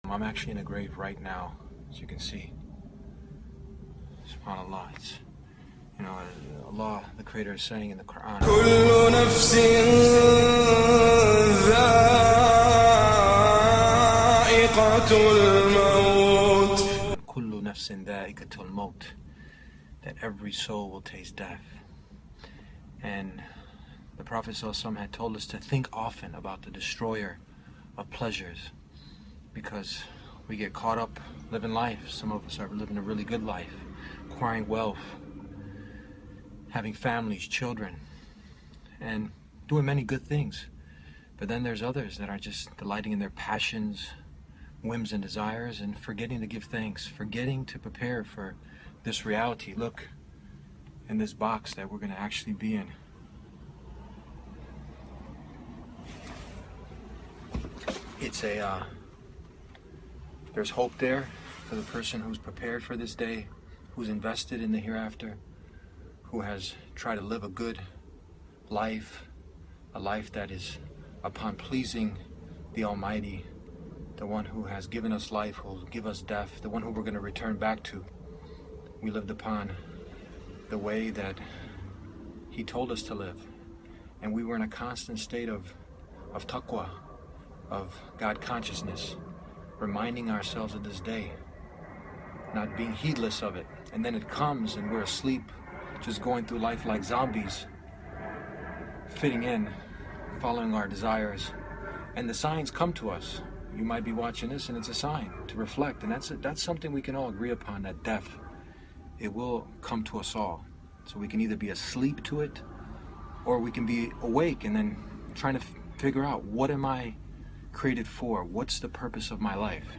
Standing inside an actual grave, this powerful reminder strips away every distraction and forces us to confront the one reality no human being can escape: death.